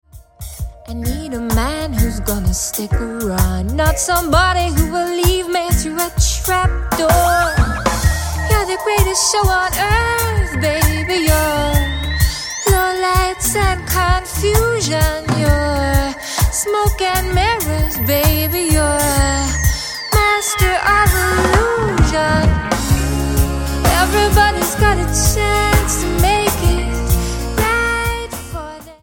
STYLE: Roots/Acoustic
swaying neo reggae
spine-tingling, sultry voice